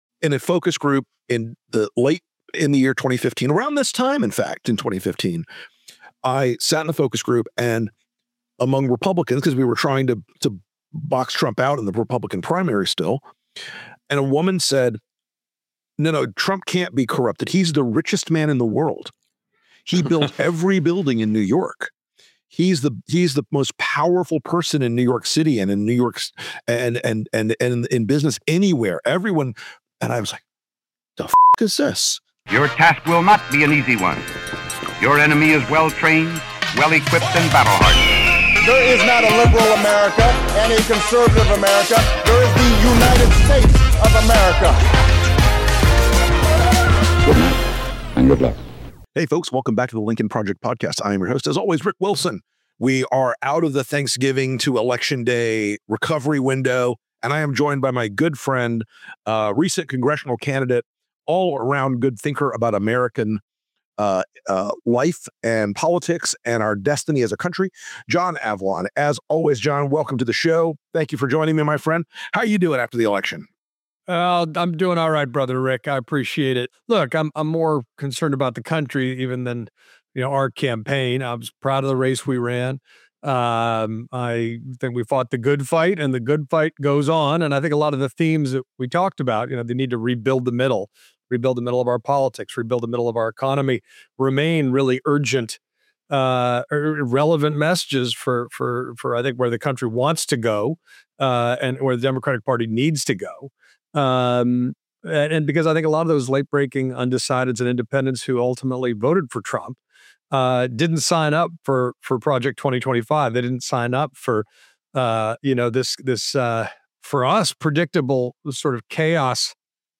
John Avlon joins Rick for a come-to-Jesus discussion about what Democrats got wrong in 2024.